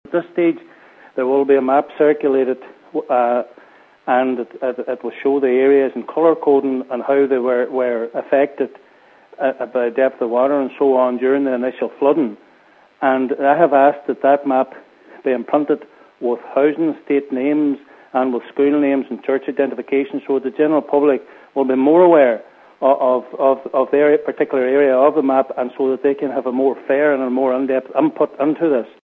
Among those who have regularly raised the issue in the council chamber is Cllr Gerry Crawford, he says final confirmation of the public consultation process is very welcome: